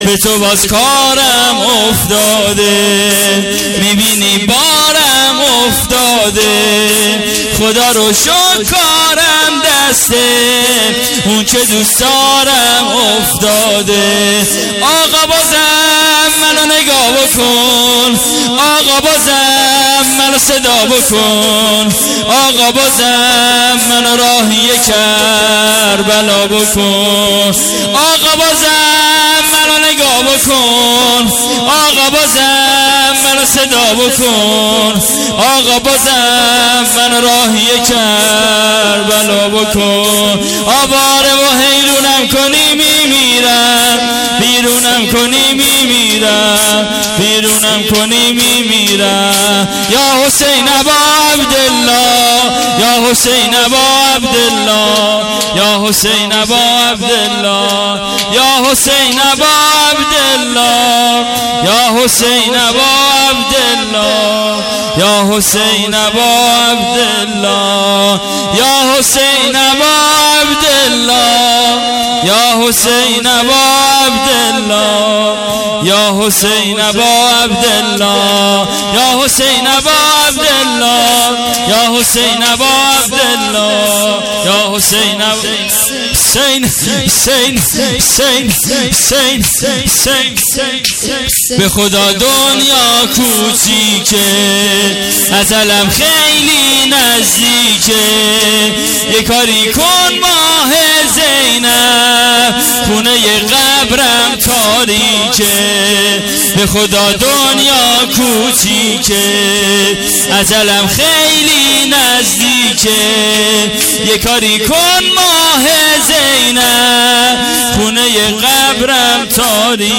هیئت عاشورا-قم